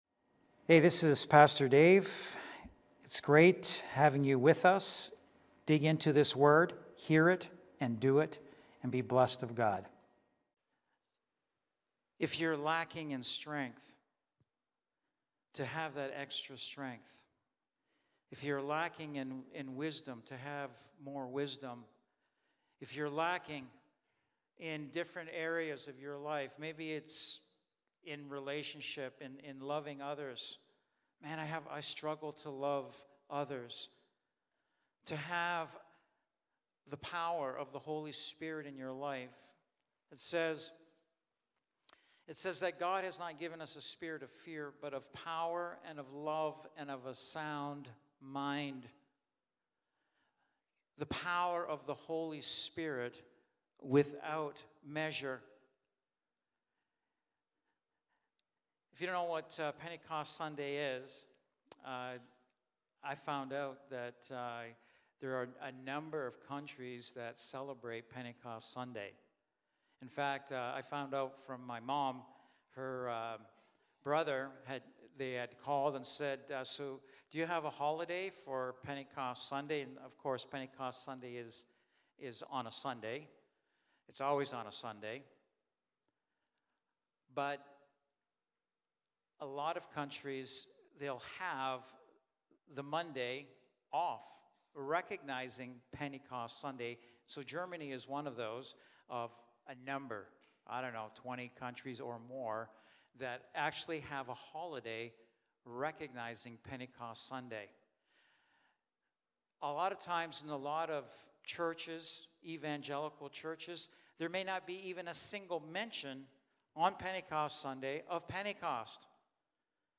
Sunday Morning Service
Lighthouse Niagara Sermons